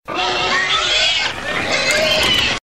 • PIGS SQUEALING.mp3
A couple of pigs squealing in a small farm hen house.
pigs_squealing_vbk.wav